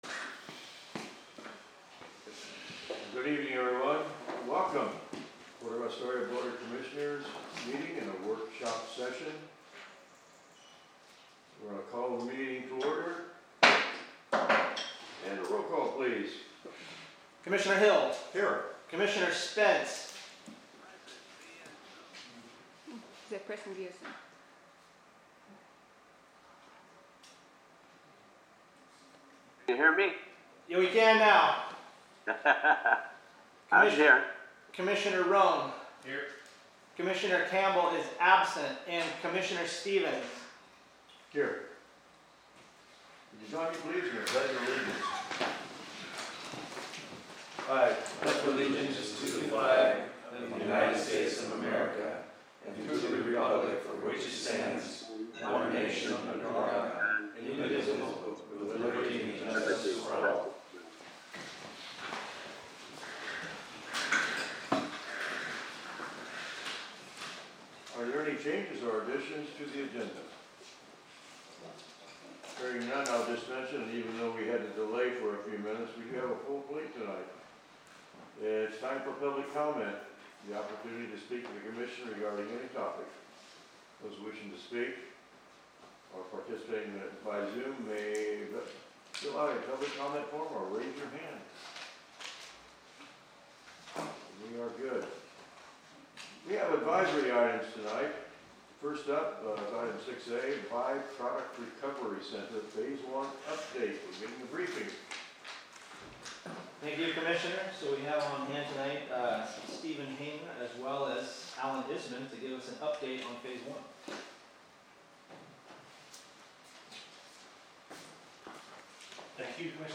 Commission Meeting
422 Gateway Avenue Suite 100, Astoria, OR, at 4 PM